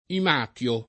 im#tLo] s. m. (stor.); pl. -ti (raro, alla lat., -tii) — il vestito di lana degli antichi greci: chiusa Nelle innumerevoli pieghe Dell’imàtio [kL2Sa nelle innumer%voli pL$ge dell im#tLo] (D’Annunzio) — anche imation [im#tLon] e, più propr., ἱμάτιον / himátion [gr. ant. him#tLon]